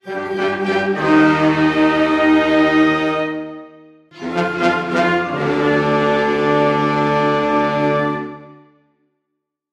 Оркестр открывает концерт